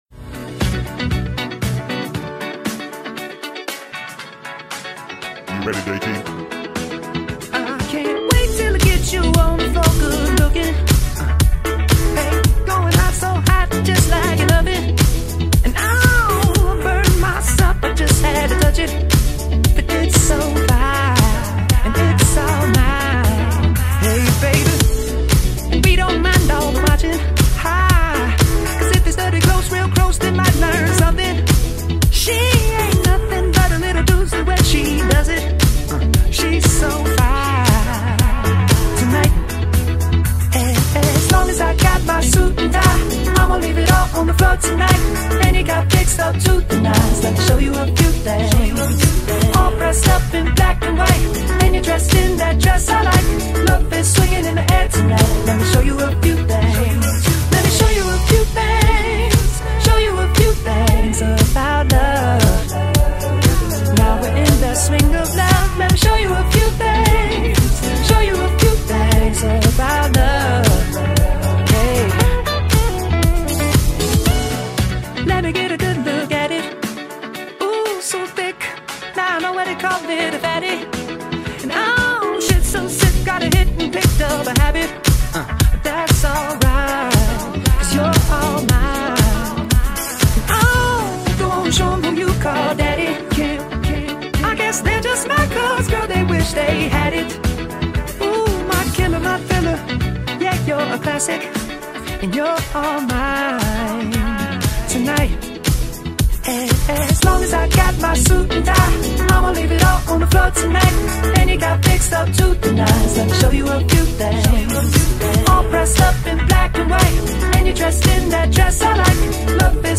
(MASHUP)